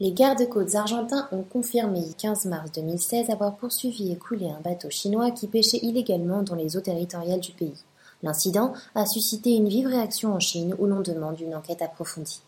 Vidéo de la Préfecture navale argentine montrant l'opération